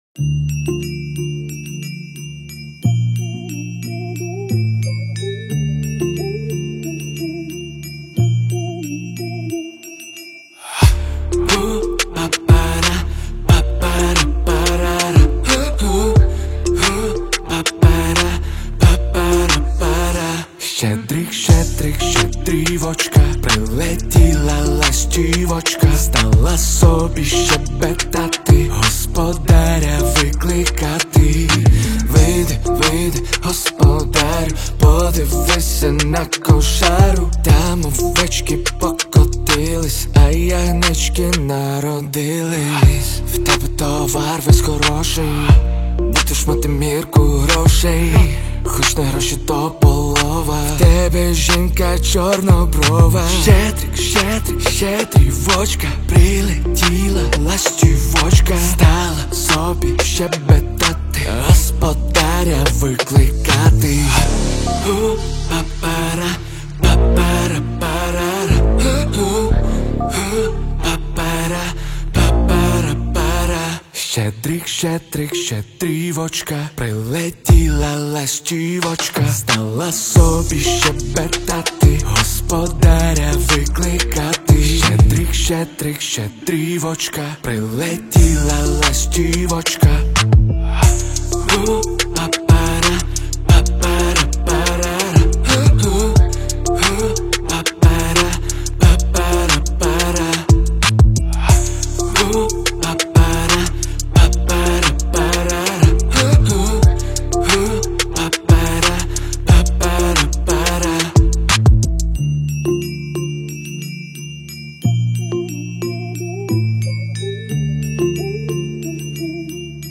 • Жанр:Поп, Народна